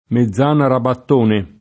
meZZ#na secondo i casi] top. — con -z- sorda un comune in val di Sole (Trent.), con -z- sonora gli altri luoghi — tra questi: Mezzana Bigli [mezz#na b&l’l’i] (Lomb.), Mezzana Mortigliengo [mezz#na mortil’l’%jgo o mezz#na mortil’l’$jgo] (Piem.), Mezzana Rabattone [